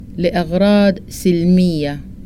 Sudanese Arabic Vocabulary List